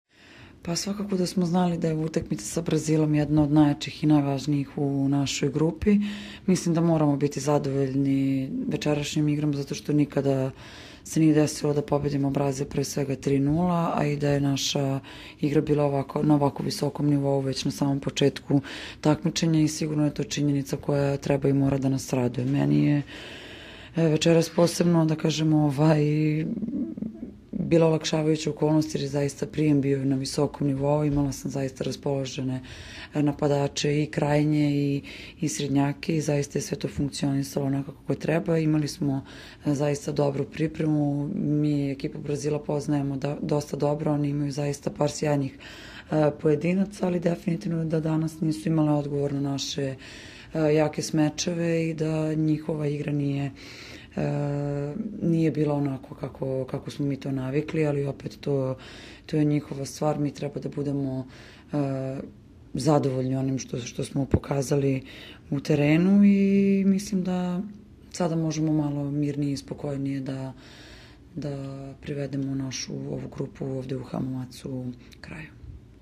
Izjava Maje Ognjenović